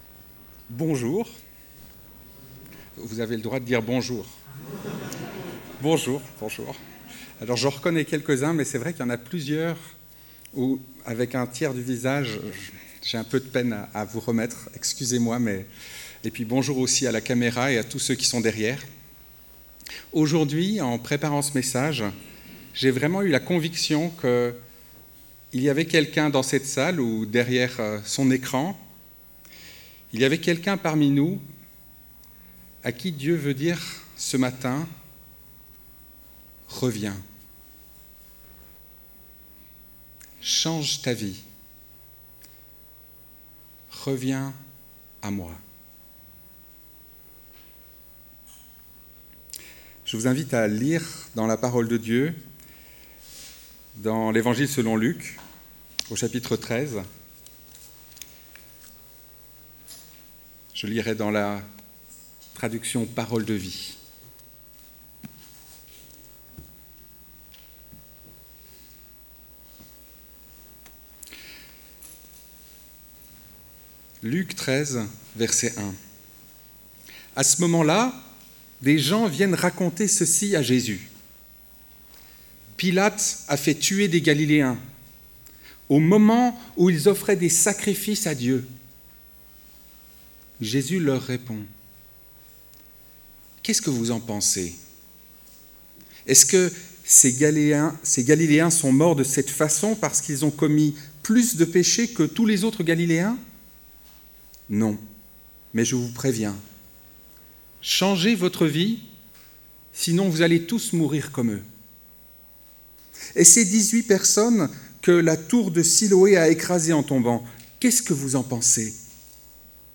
Culte du 1er novembre 2020 « Face à la violence »